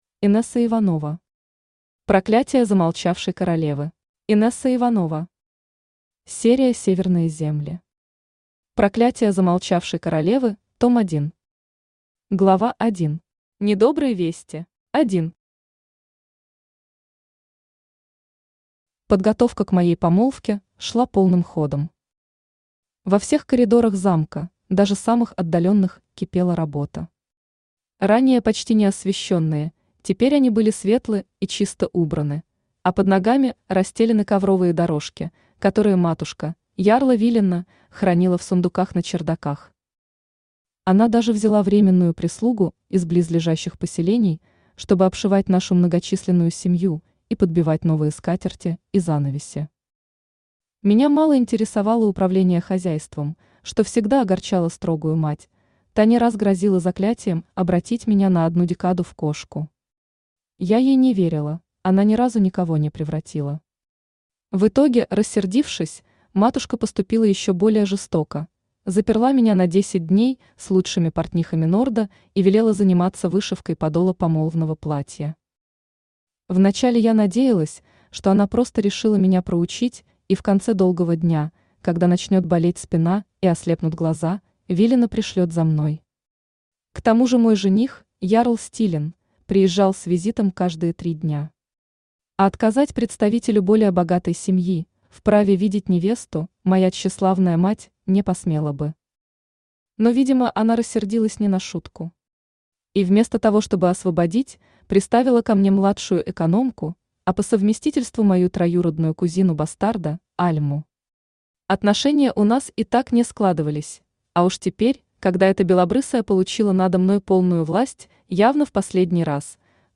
Аудиокнига Проклятие замолчавшей королевы | Библиотека аудиокниг
Aудиокнига Проклятие замолчавшей королевы Автор Инесса Иванова Читает аудиокнигу Авточтец ЛитРес.